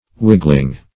Whigling \Whig"ling\, n. A petty or inferior Whig; -- used in contempt.
whigling.mp3